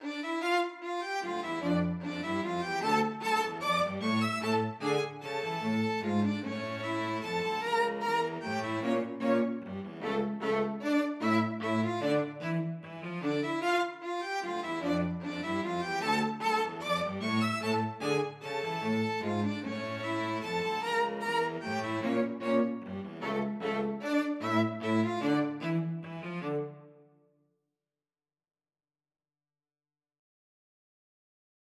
하이든, 현악 사중주 D단조, Op. 76, No. 2의 미뉴에트